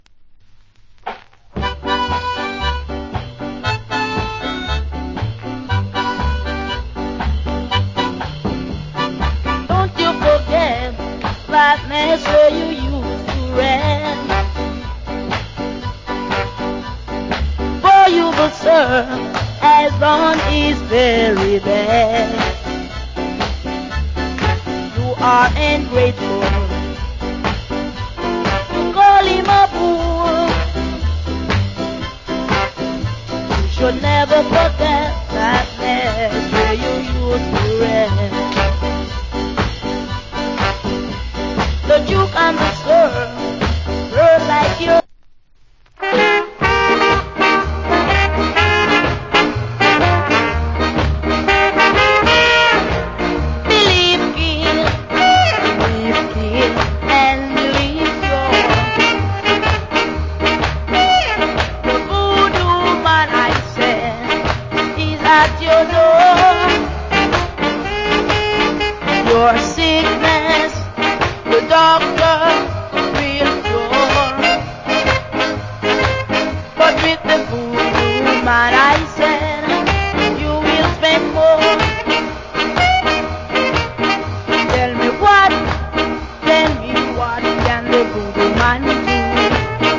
Great Ska Vocal.